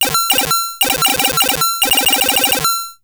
Retro Video Game Blip 4.wav